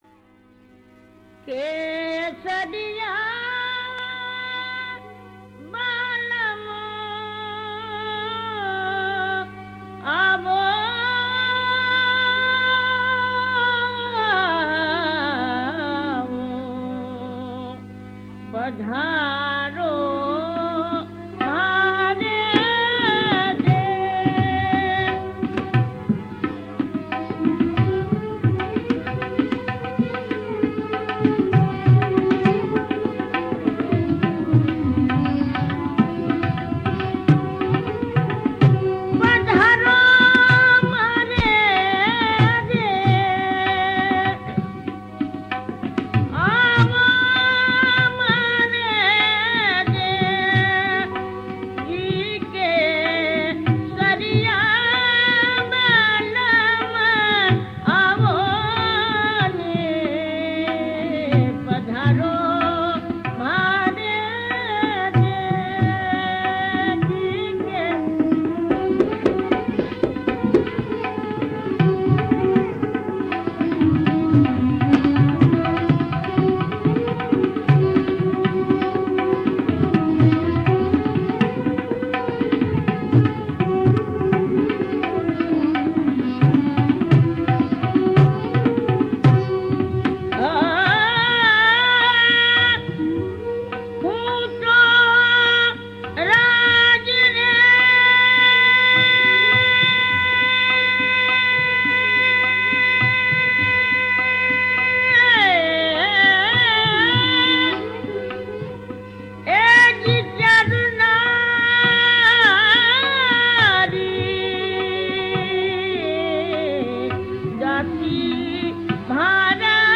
Rajasthani Songs